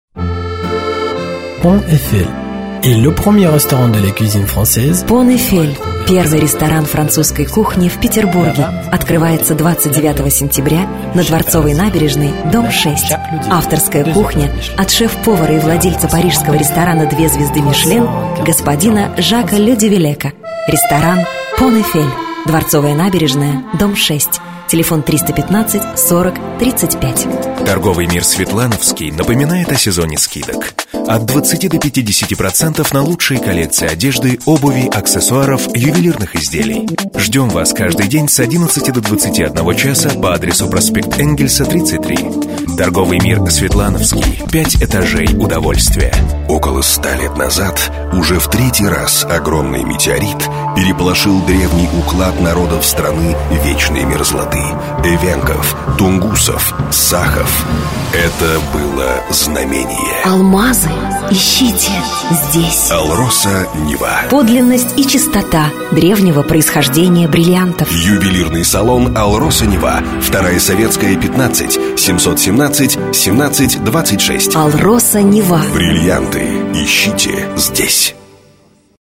исходник гораздо чище